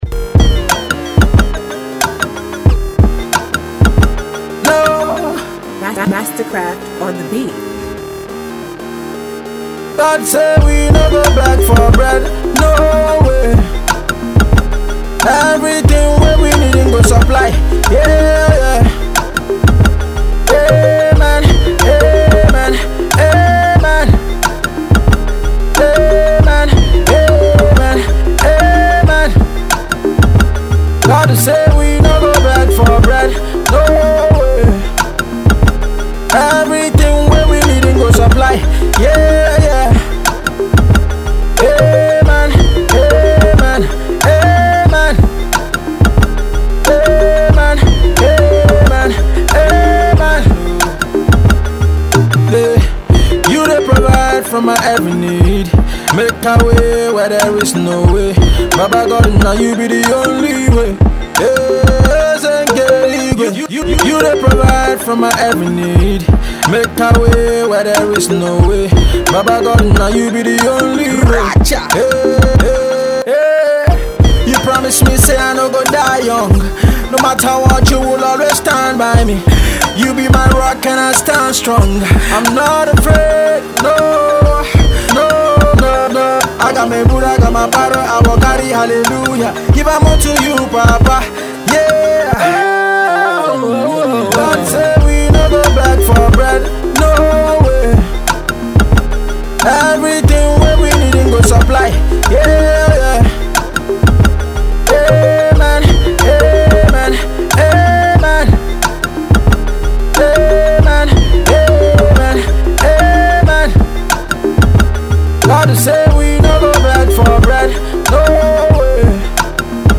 a song of prayer
a Nigerian indigenous rapper